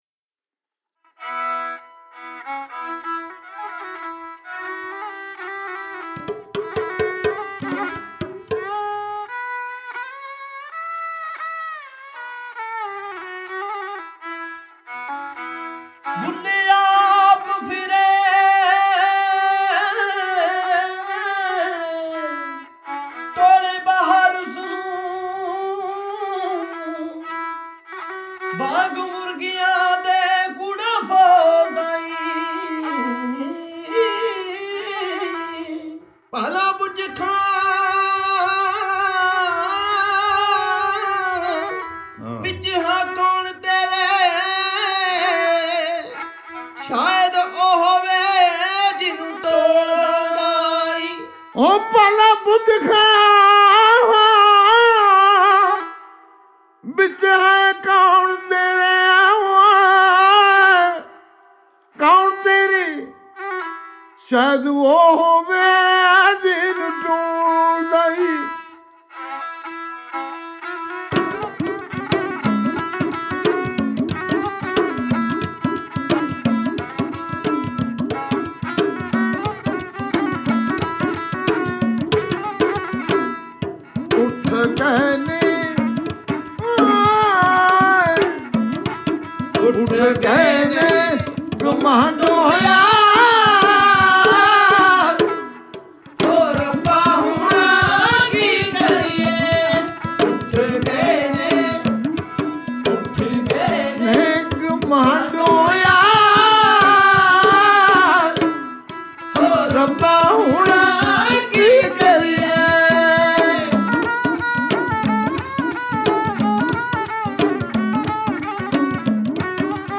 Kalaam/Poetry, Punjabi